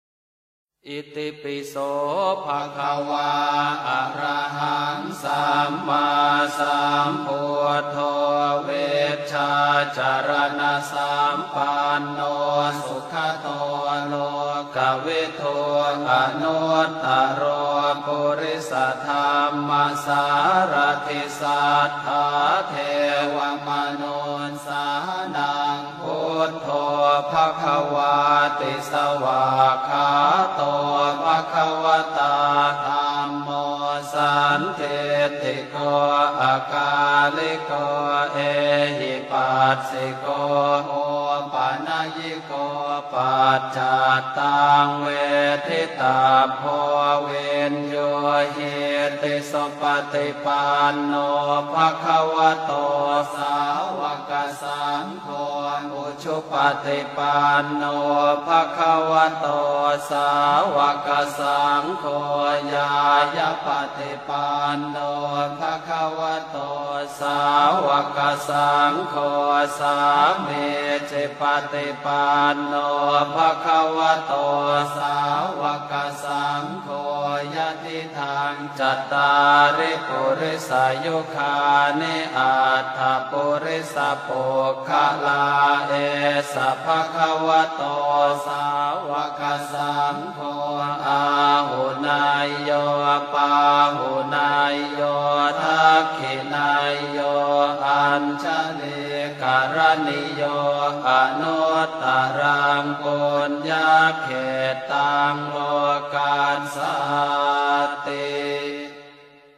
ITIPISO_Chant_Bouddhiste_Traduction_Francaise.mp3